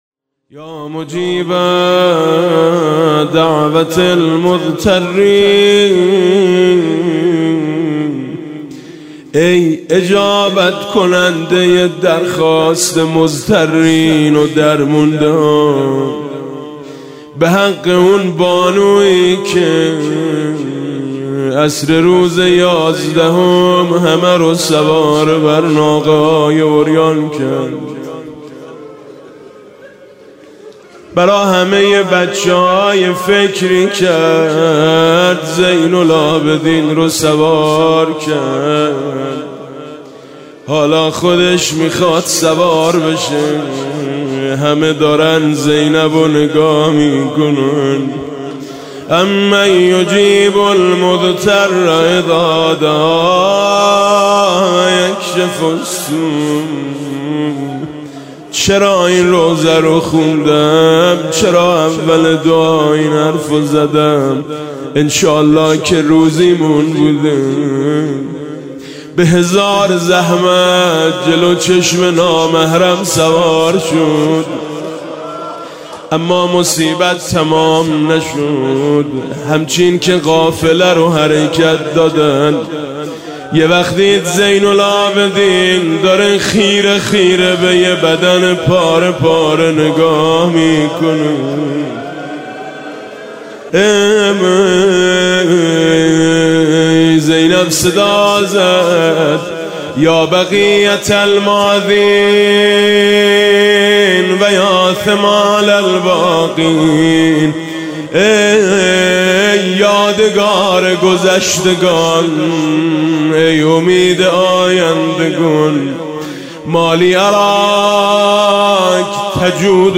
14رمضان98-روضه: اضطرار زینب کبری (س) در عصر روز یازدهم